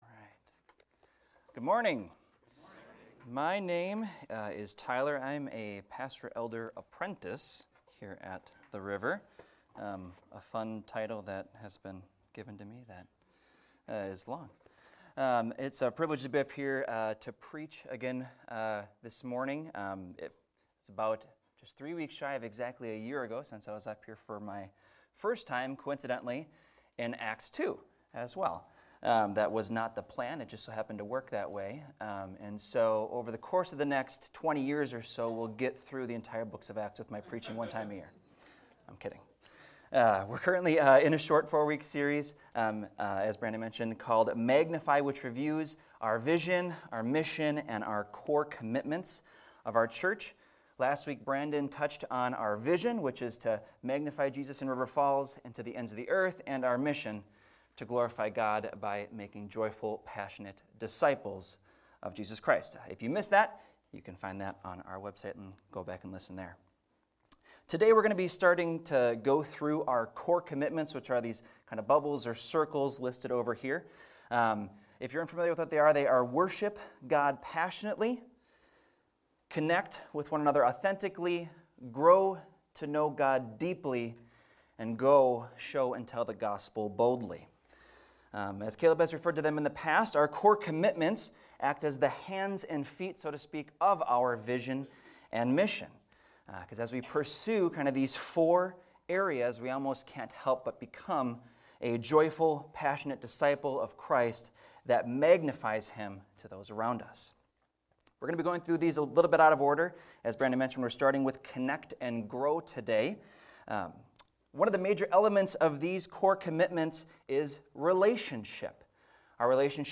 This is a recording of a sermon titled, "Fellowship of Believers."